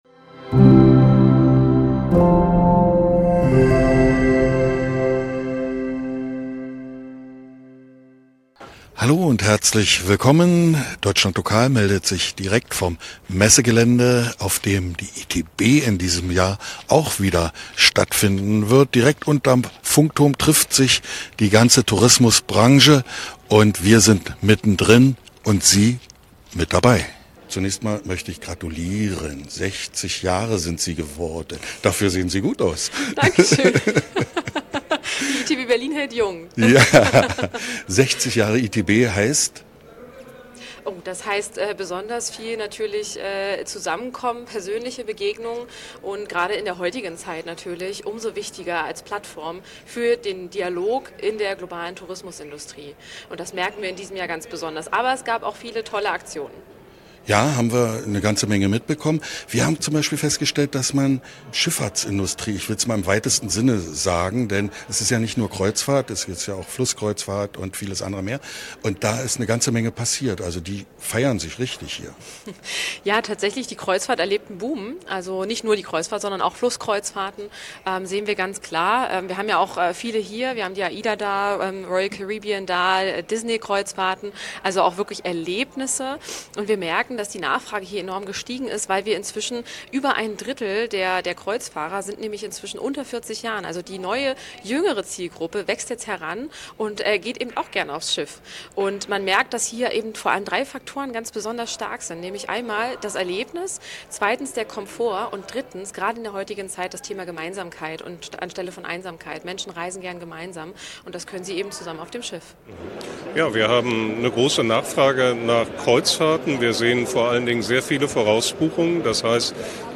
Alle befragten in Erfurt, ob jung oder �lter, haben noch keine Corona-Warn-App geladen. Die Gr�nde daf�r sind unterschiedlich und hier zu erfahren. Einige Vor- und Nachteile gibt es von der Dame mit dem Mikro mit dazu.